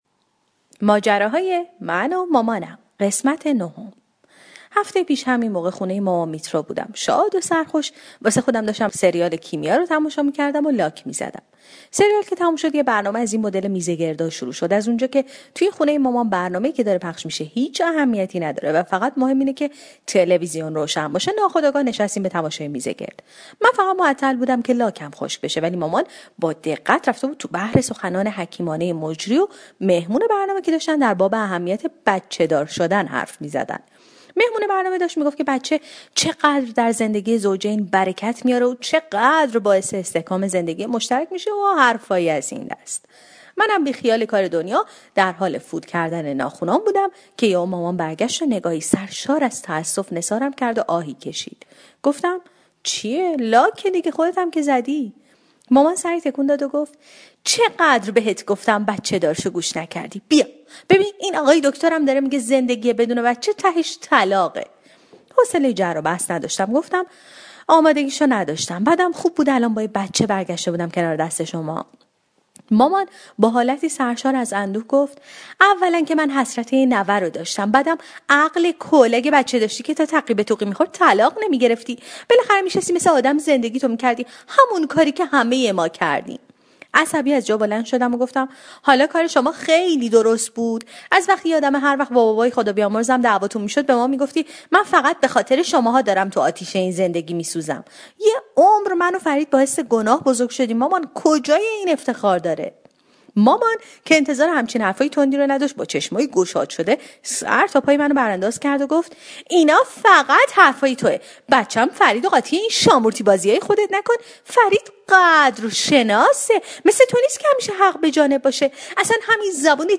طنزصوتی/ ماجراهای من و مامانم ۹